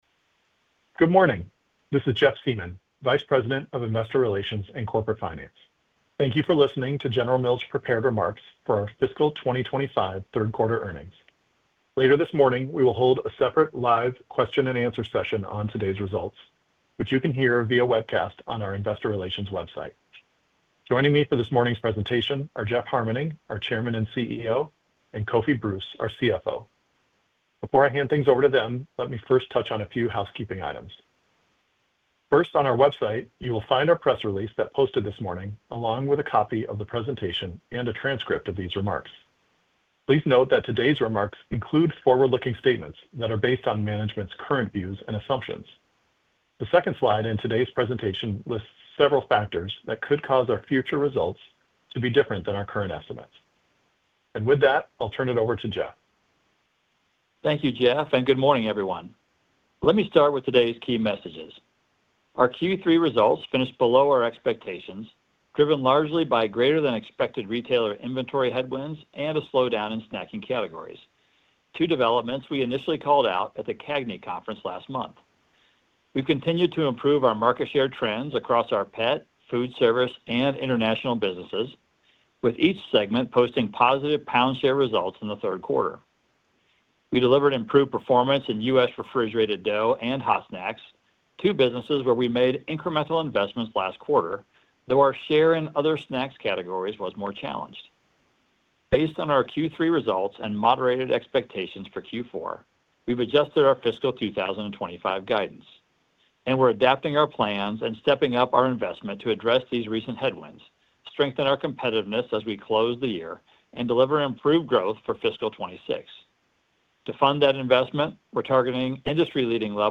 Recording - Prepared Remarks (opens in new window)
V3_PRE-RECORD-General-Mills-Inc-Q3-F25-Earnings-Call.mp3